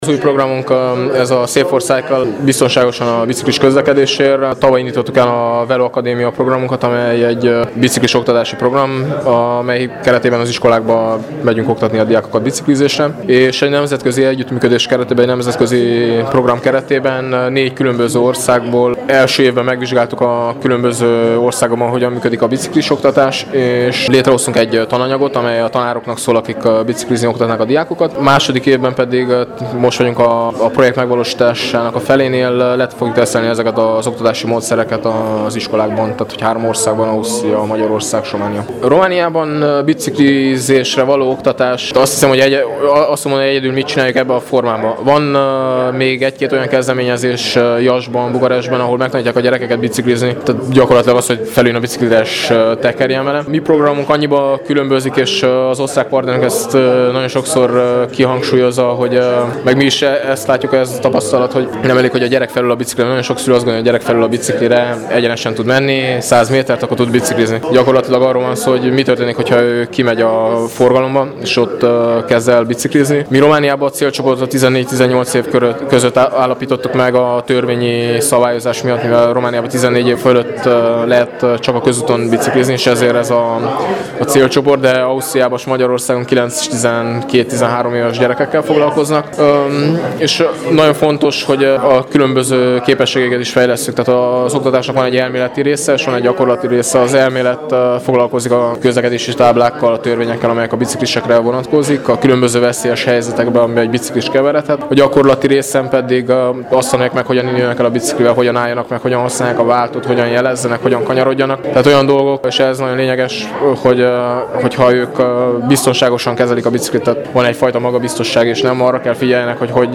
A riportokat